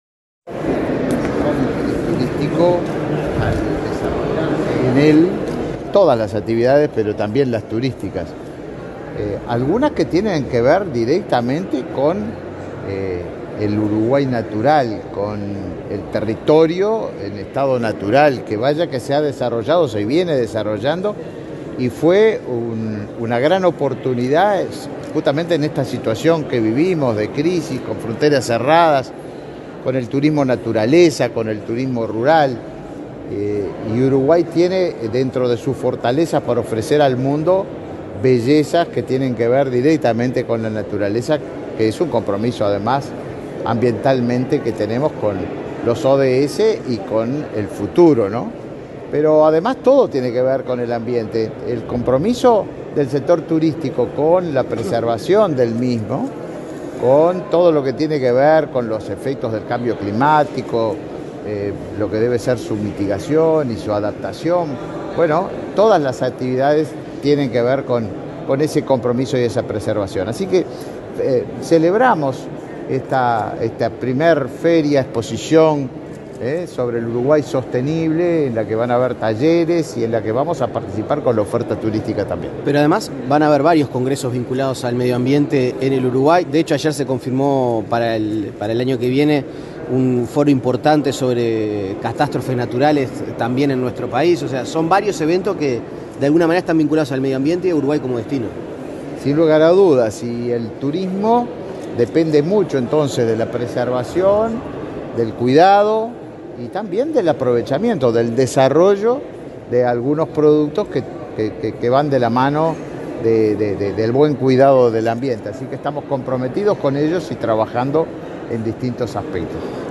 Declaraciones a la prensa del ministro de Turismo, Tabaré Viera
Luego, el ministro de Turismo, Tabaré Viera, dialogó con la prensa.